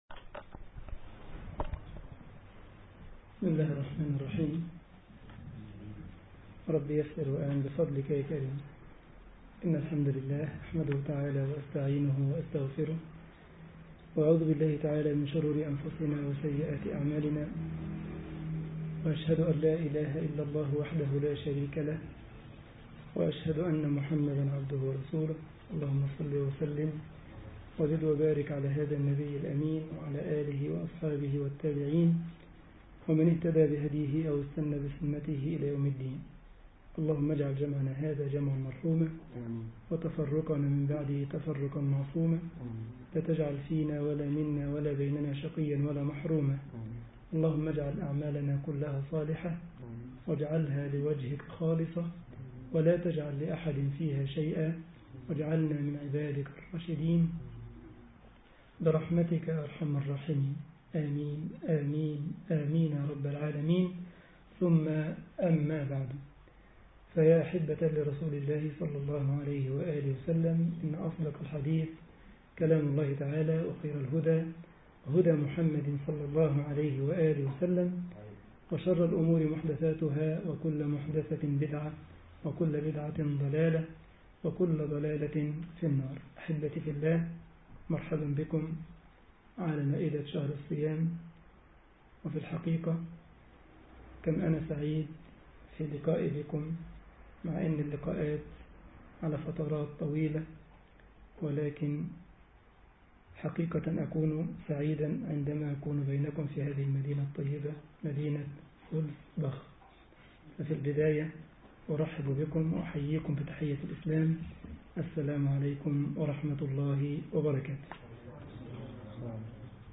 مسجد مدينة زولتسباخ ـ ألمانيا درس رمضان